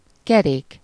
Ääntäminen
Ääntäminen Tuntematon aksentti: IPA: /jʉːl/ Haettu sana löytyi näillä lähdekielillä: ruotsi Käännös Ääninäyte Substantiivit 1. kerék Artikkeli: ett .